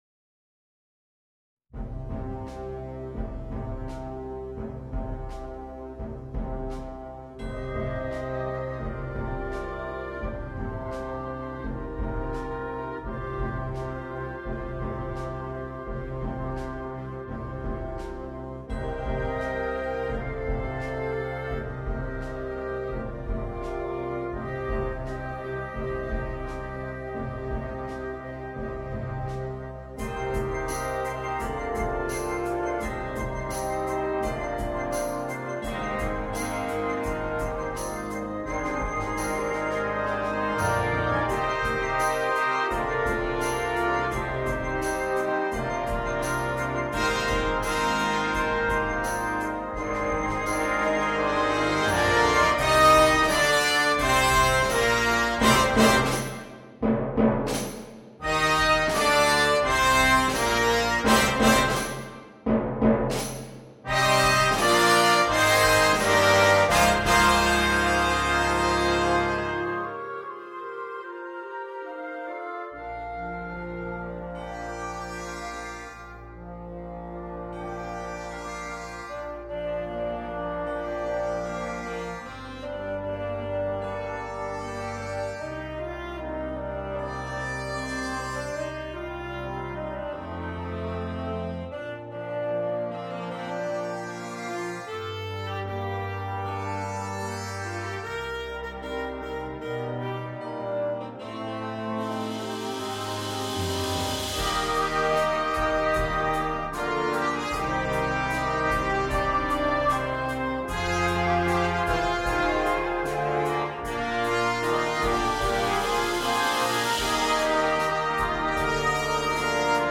на духовой оркестр.